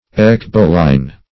Search Result for " ecboline" : The Collaborative International Dictionary of English v.0.48: Ecboline \Ec"bo*line\ (?; 104), n. [Gr.